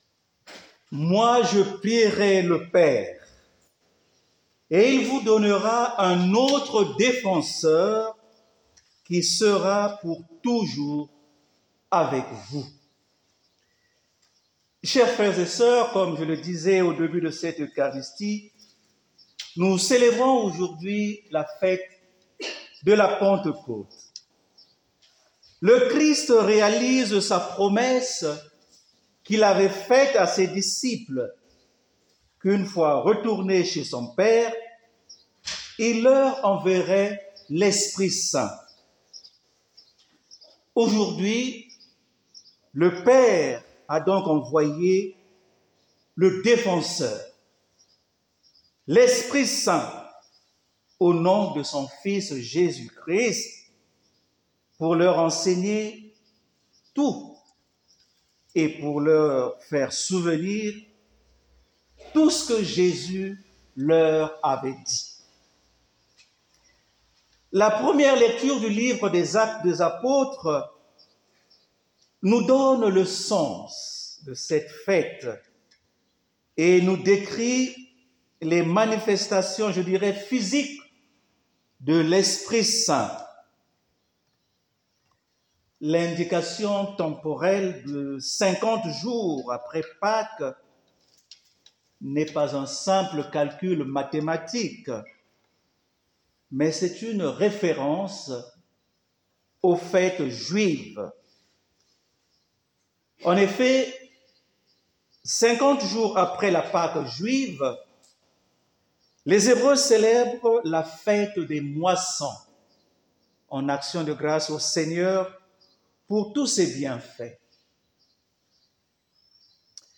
Grâce à un enregistrement en direct, ceux qui le souhaitent peuvent écouter sa réflexion.